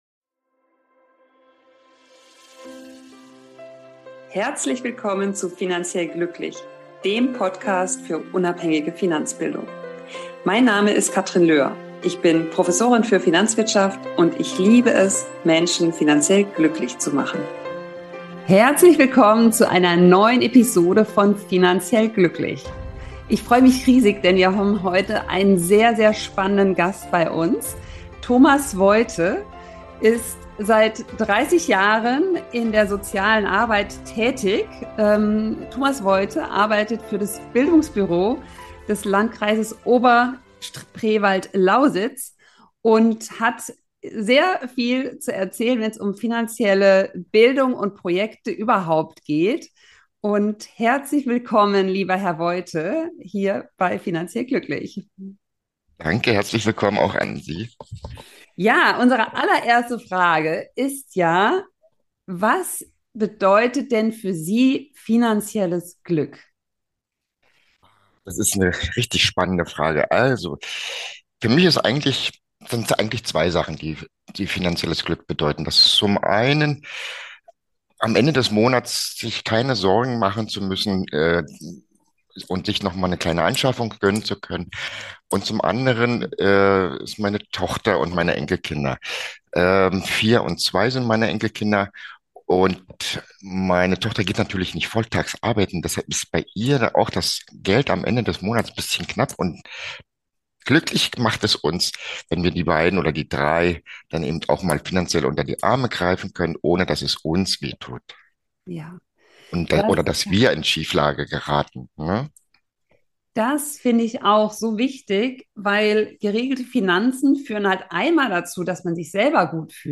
Interview: Finanzbildung als Teil der Sozialen Arbeit (Repost) ~ Finanziell glücklich. Podcast